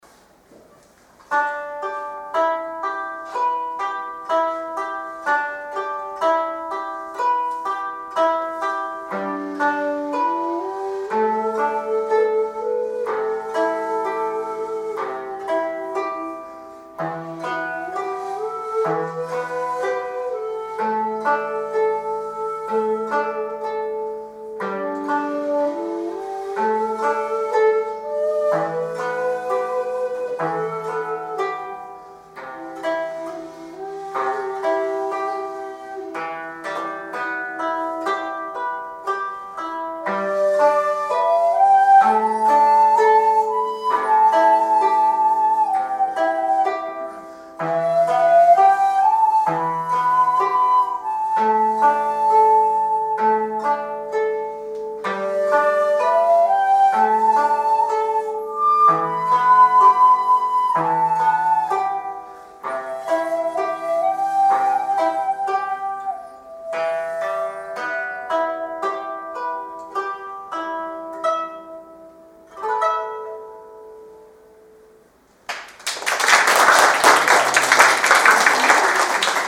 小学校邦楽鑑賞会での演奏
なんと言っても尺八･琴の音色にぴったりの曲です。
子守歌と追分は地無し管を使用しています。